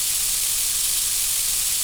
gas_leak_03_loop.wav